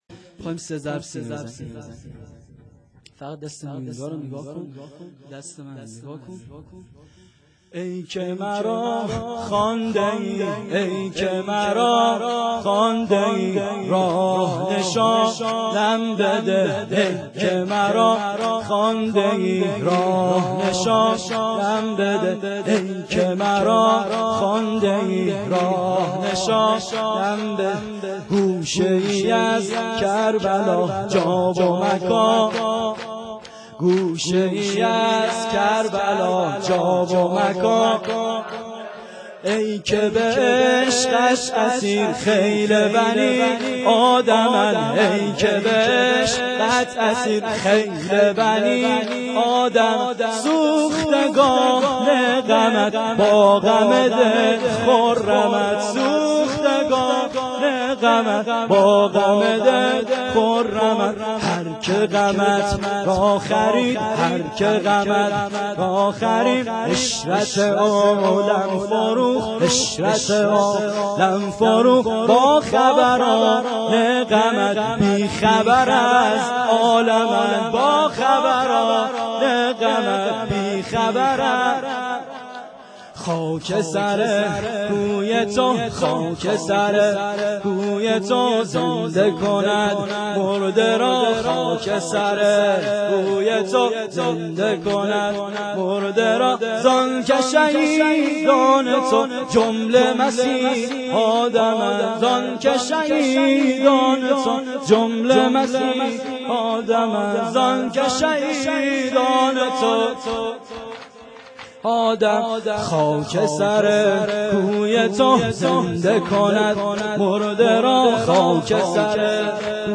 سه ضرب